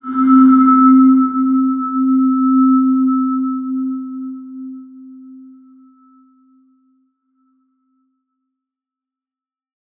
X_BasicBells-C2-pp.wav